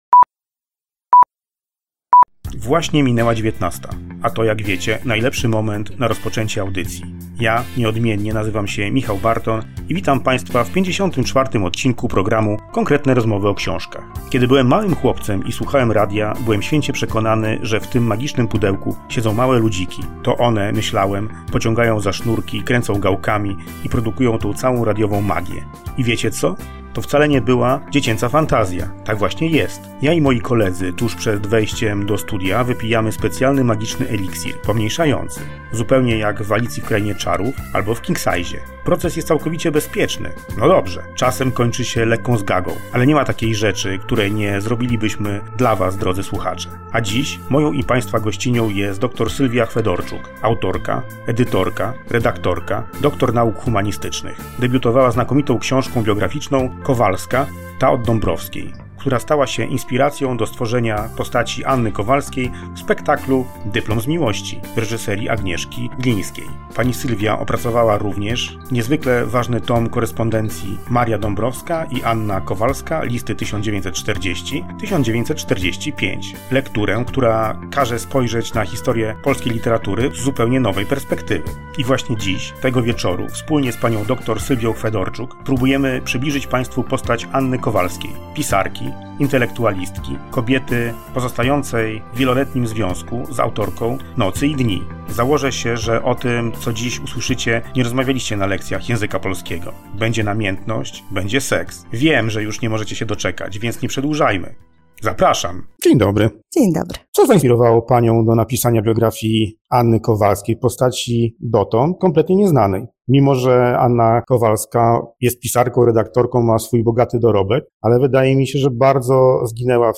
zaprosi do studia wyjątkową gościnię